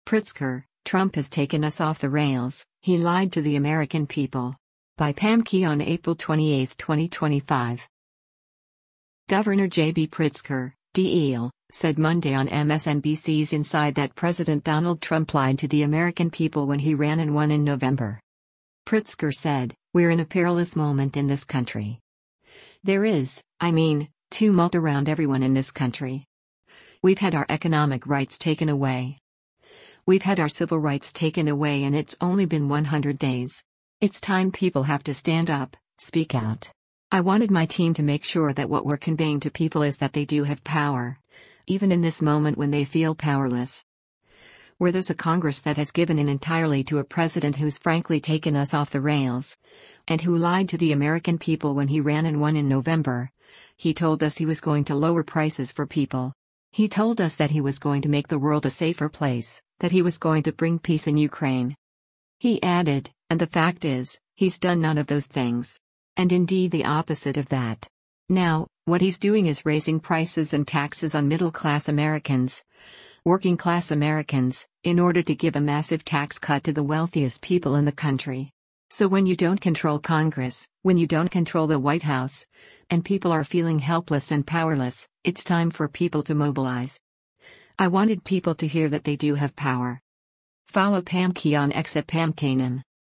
Governor JB Pritzker (D-IL) said Monday on MSNBC’s “Inside” that President Donald Trump “lied to the American people when he ran and won in November.”